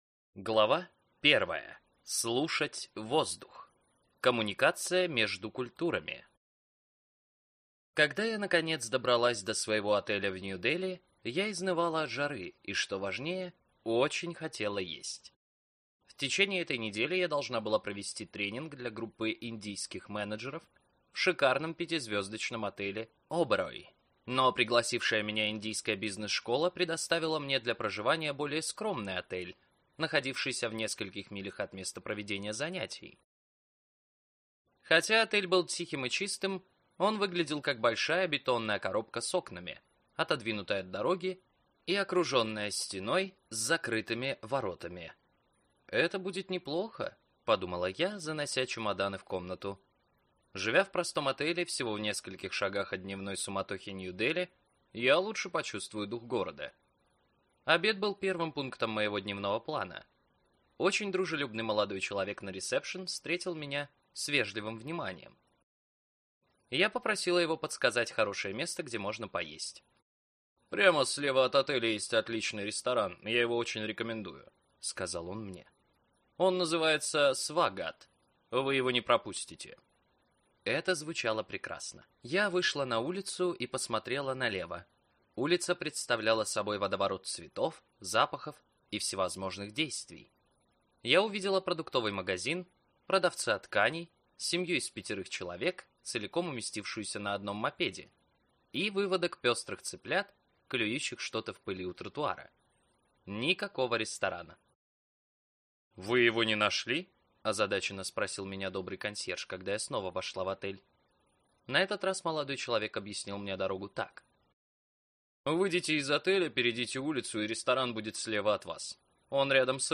Аудиокнига Карта культурных различий. Как люди думают, руководят и добиваются целей в международной среде | Библиотека аудиокниг